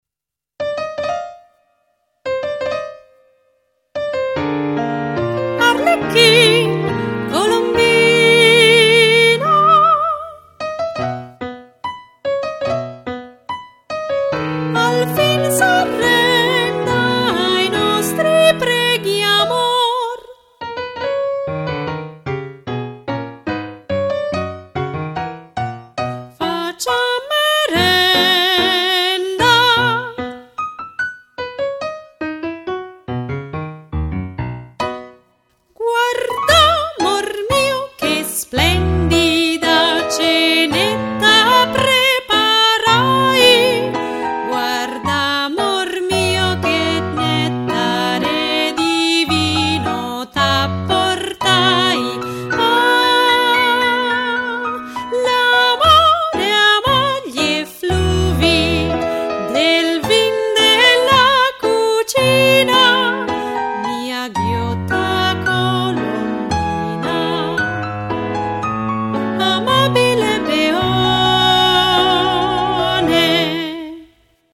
Arlecchin! Colombina! – Ritmica